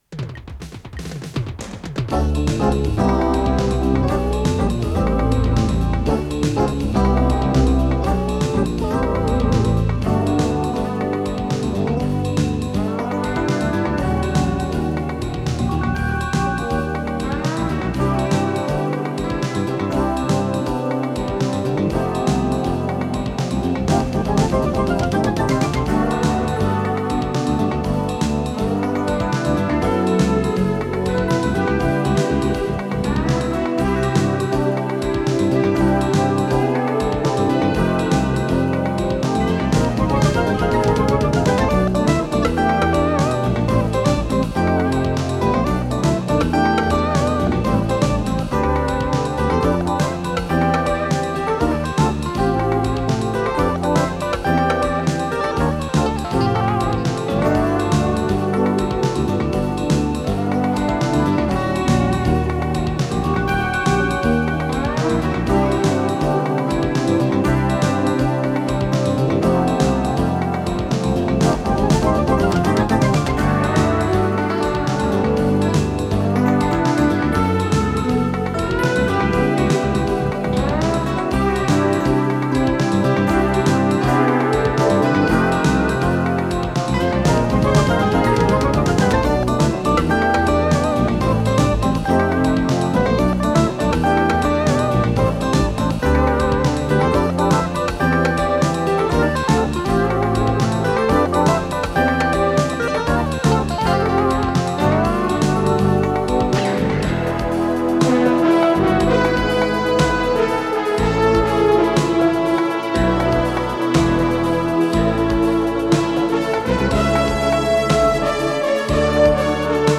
с профессиональной магнитной ленты
дуэт электронной музыки
ВариантДубль моно